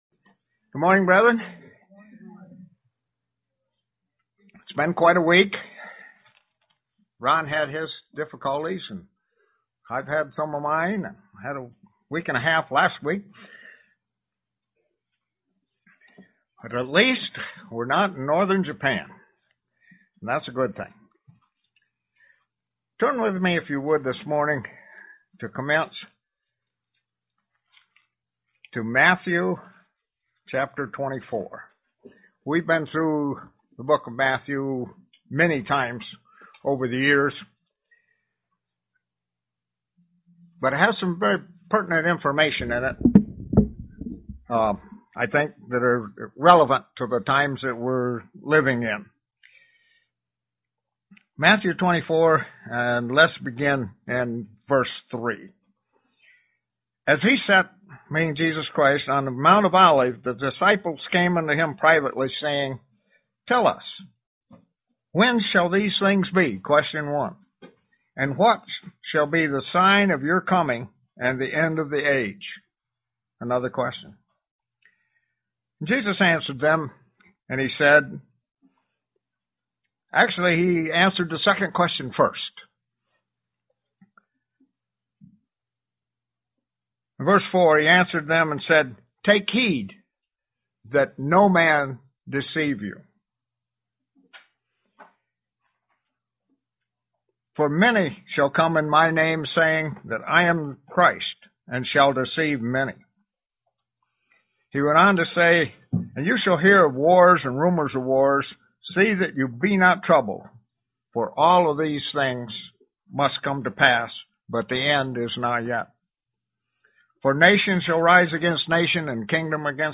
Print What are we to watch for to signal the end times UCG Sermon Studying the bible?
Given in Elmira, NY